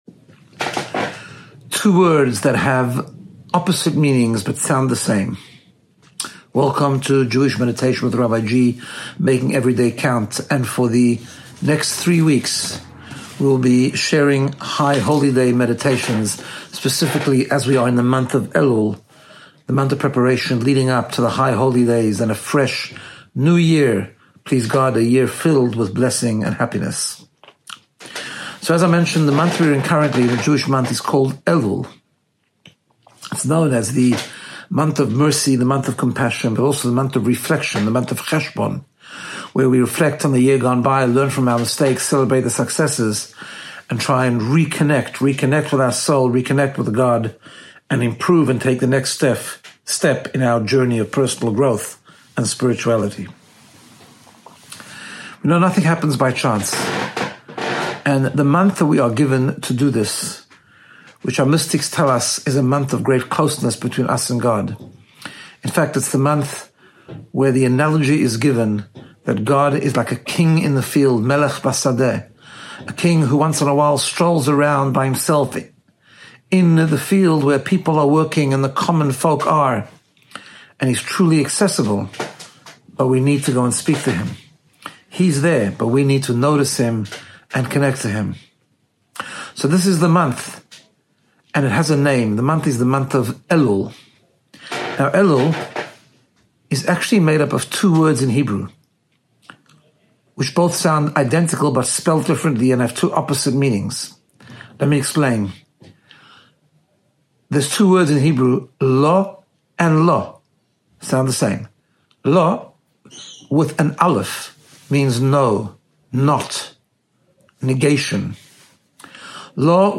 High Holy Day Meditations